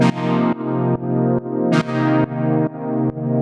GnS_Pad-dbx1:4_140-C.wav